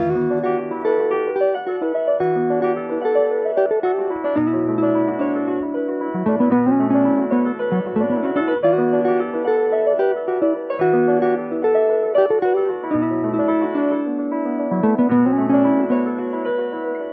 ~PIANO JAZZ~.wav